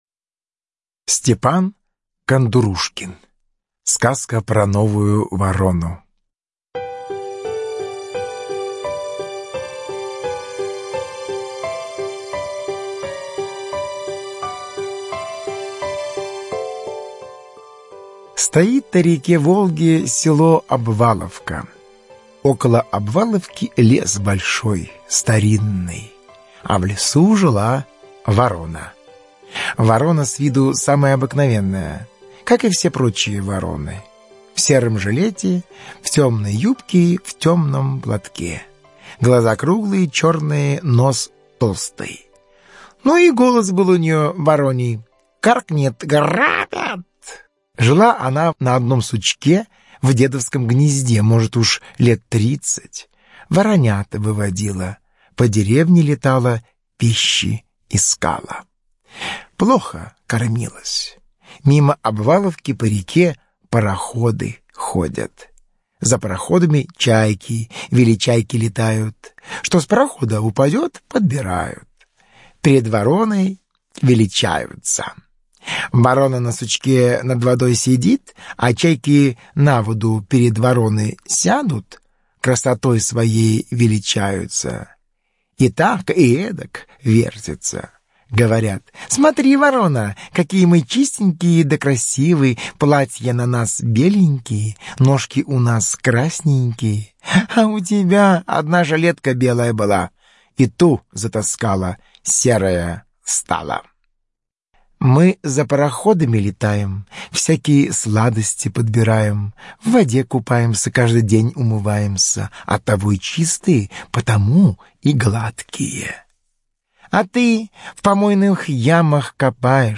Сказка про новую ворону - аудиосказка Кондурушкина - слушать онлайн